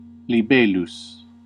Ääntäminen
IPA : /ˈliː.flɪt/ US : IPA : [ˈliː.flɪt]